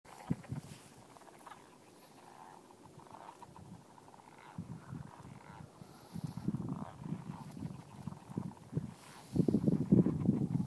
Frog sounds recorded live on 22 February 2012 at Huntley Meadows Park, Fairfax County, Virginia USA. Can you tell it was a windy day? I don't have a wind screen for the built-in mic on my iPhone 4!